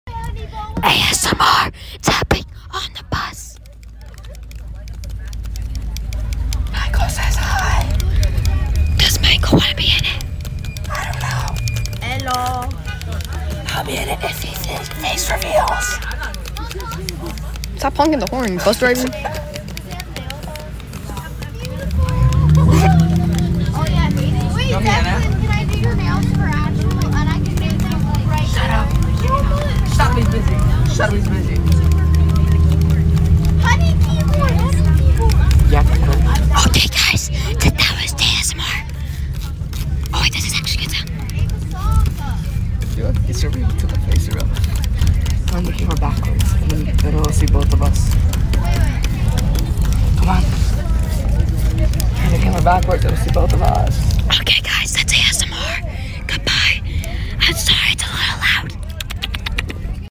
ASMR ON THE BUS!!!! sound effects free download
(Sorry for loud noises) ASMR ON THE BUS!!!!